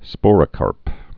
(spôrə-kärp)